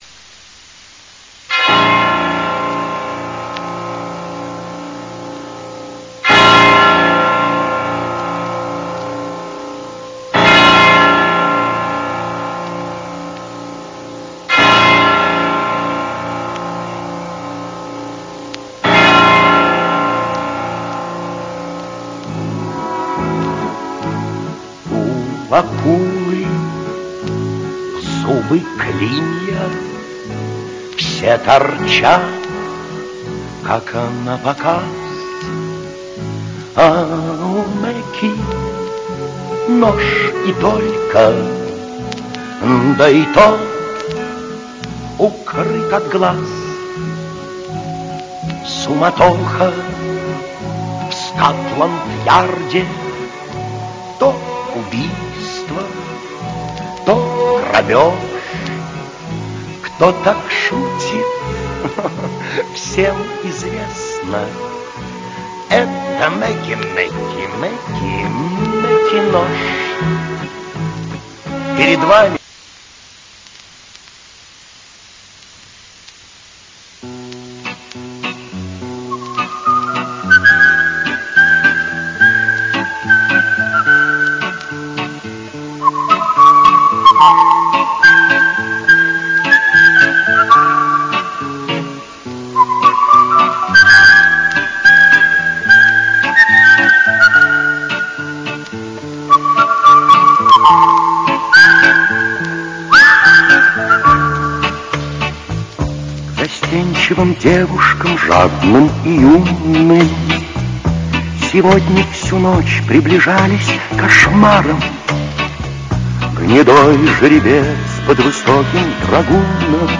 В каждом по три первых куплета.